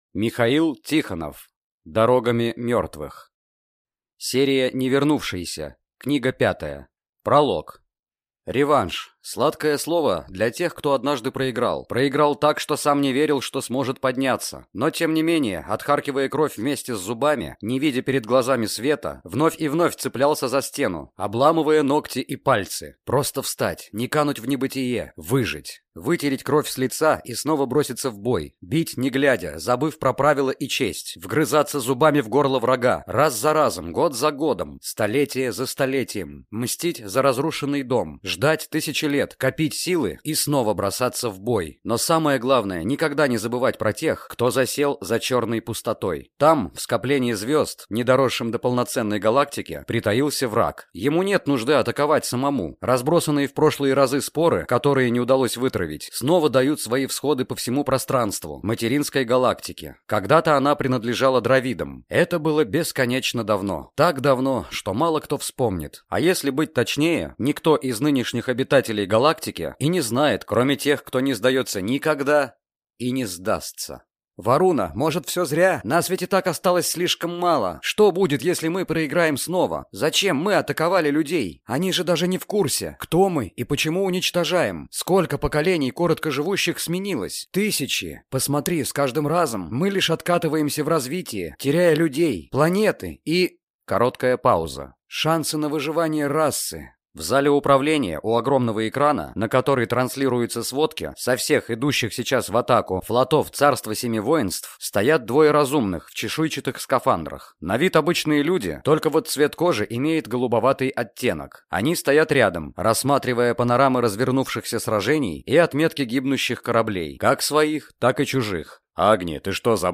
Аудиокнига Дорогами Мертвых | Библиотека аудиокниг